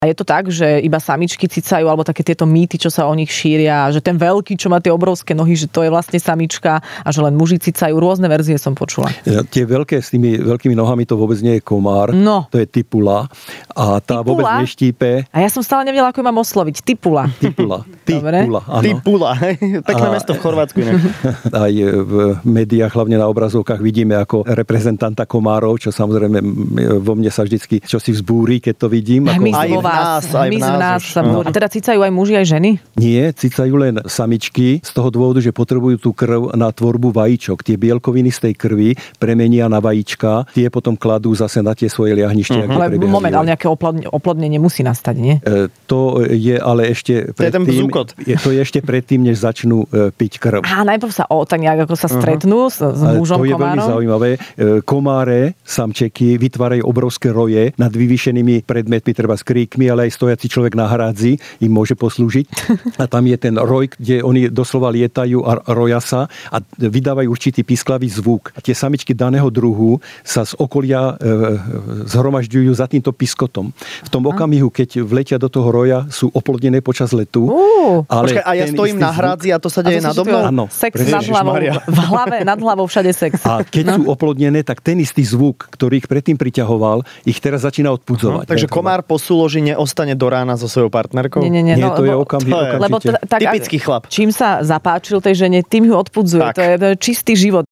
Do Rannej šou s Adelou a Sajfom dnes prišiel hmyzológ...
Toto je iba niekoľko otázok, na ktoré dnes musel odpovedať "hmyzológ" v Rannej šou s Adelou a Sajfom!